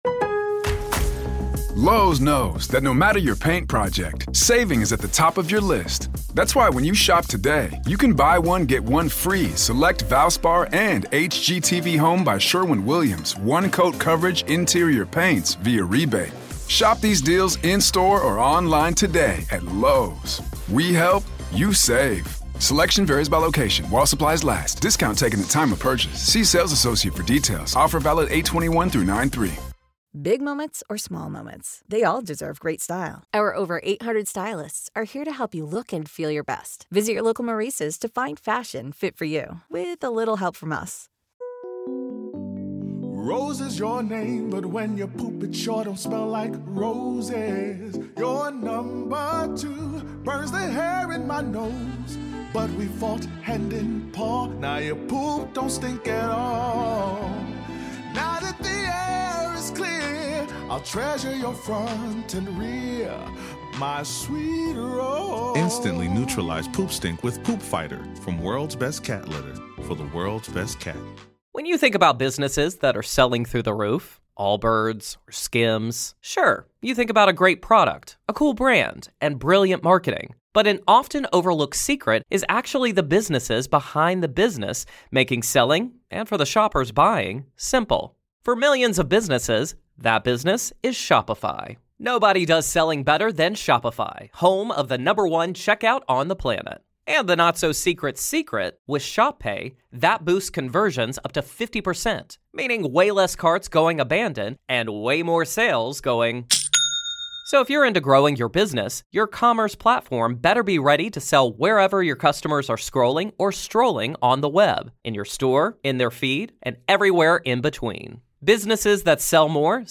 Today, on this classic episode of the Grave Talks, Part One of our conversation